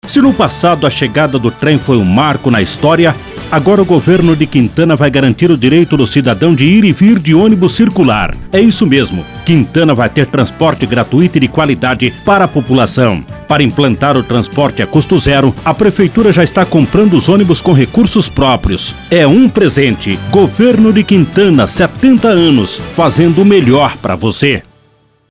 Informe publicitário: ouça o spot: